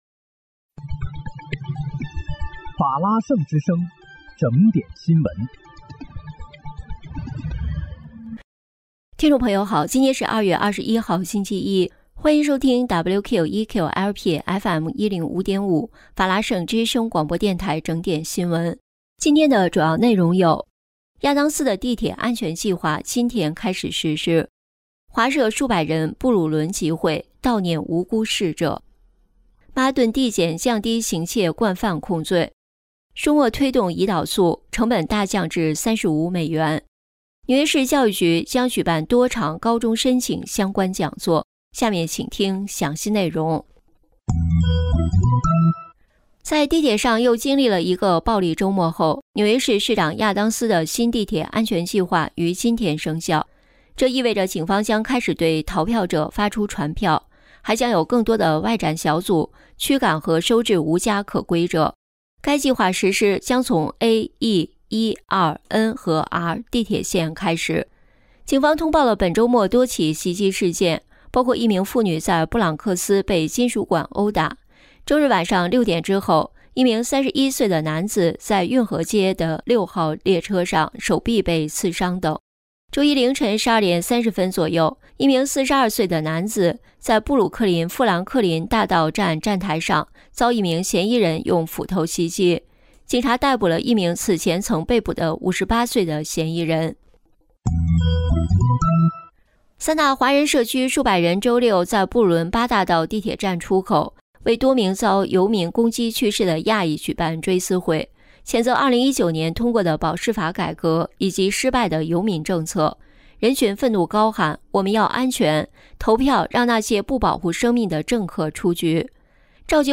2月21日（星期一）纽约整点新闻